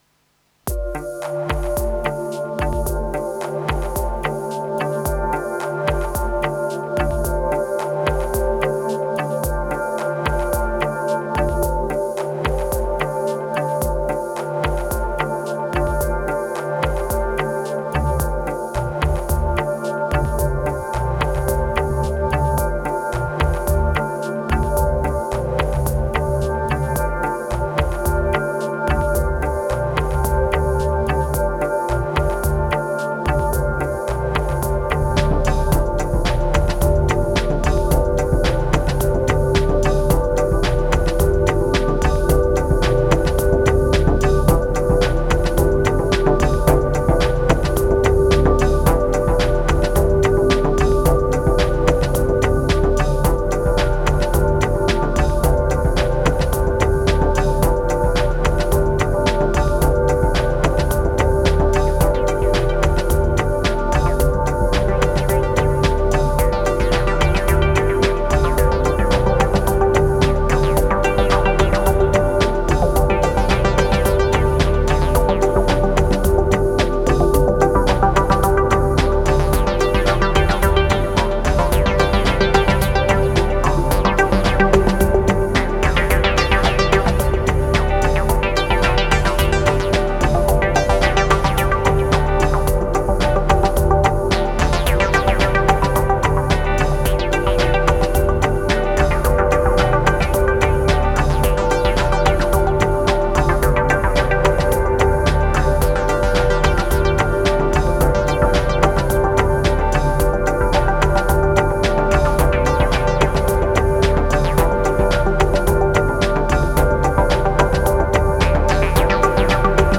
Bass obsession and 90s drums.